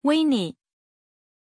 Pronunciation of Winni
pronunciation-winni-zh.mp3